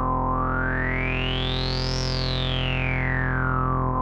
MOOG SWEEP.wav